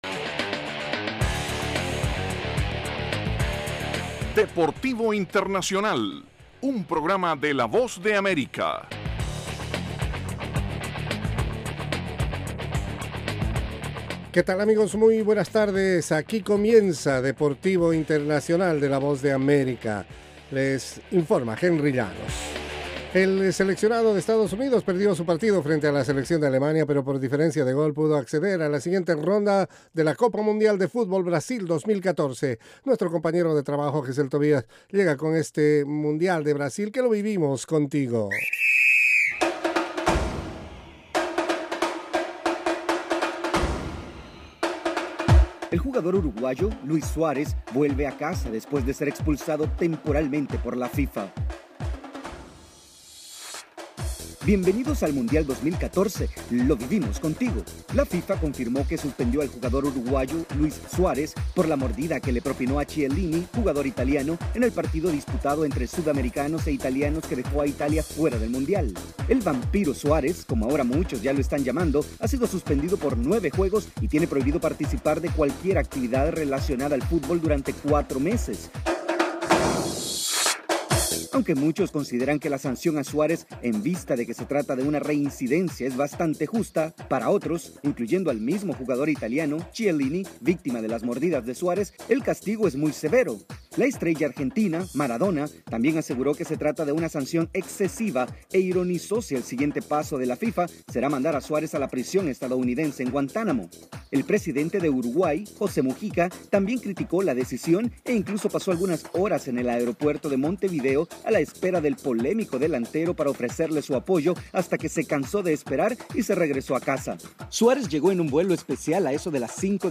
presenta las noticias más relevantes del mundo deportivo desde los estudios de la Voz de América. Deportivo Internacional se emite de lunes a viernes, de 12:05 a 12:10 de la tarde (hora de Washington).